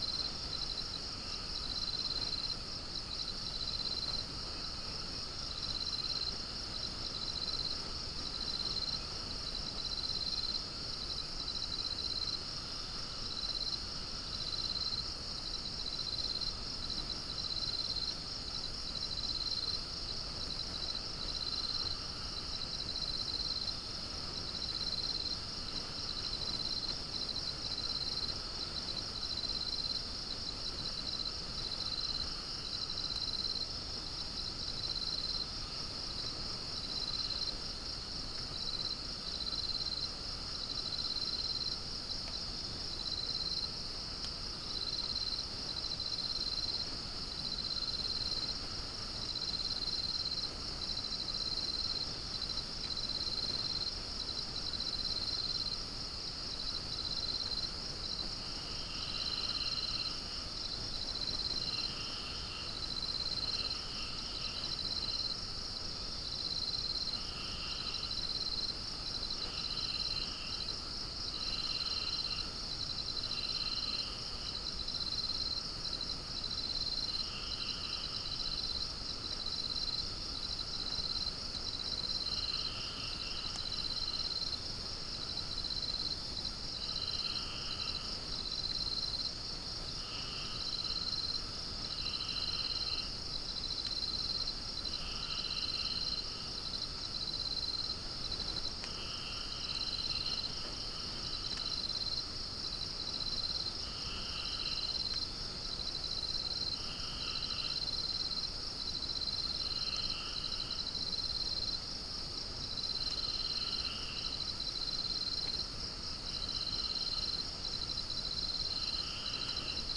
unknown bird